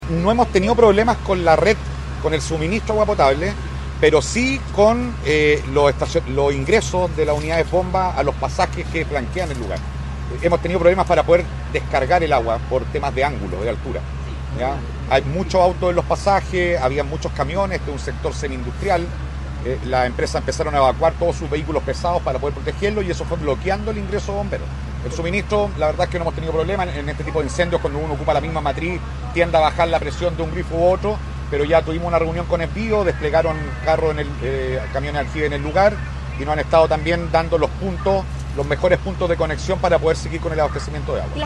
El comandante agregó que las condiciones de acceso, propias de un sector semi industrial, han dificultado la labor de Bomberos.